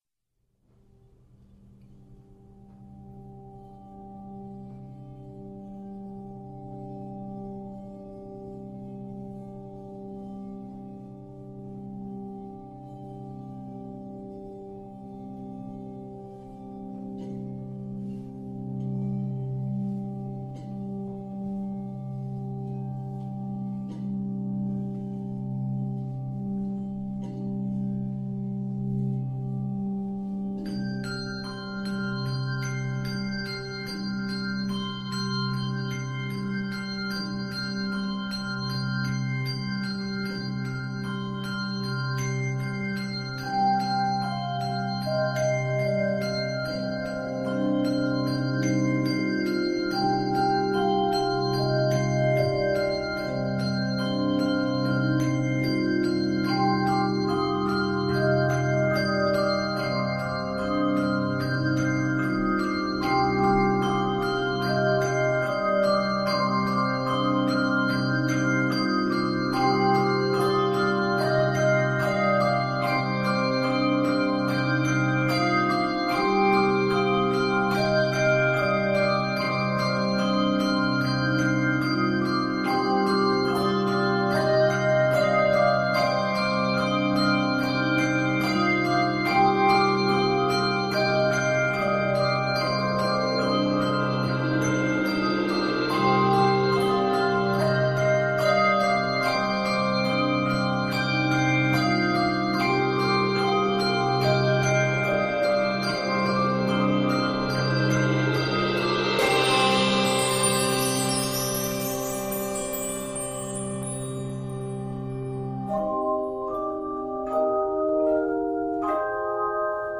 It is arranged in c minor.